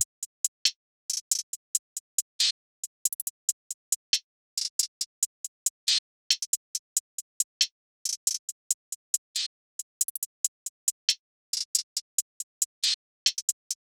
Cardiak_HiHat_Loop_5_138bpm.wav